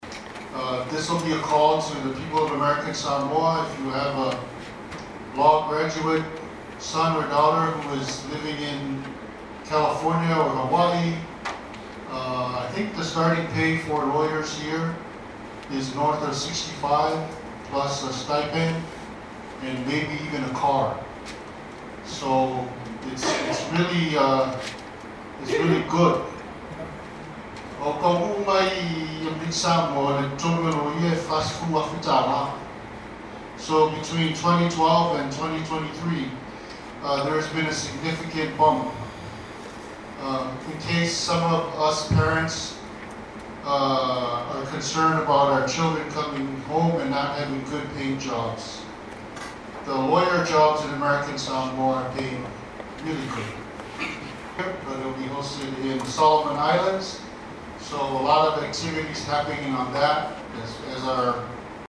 Speaking at yesterday’s cabinet meeting, he said, “We have an attorney deficiency,” and the recruitment of attorneys is not just the responsibility of the Attorney General but all of the cabinet has a role in it.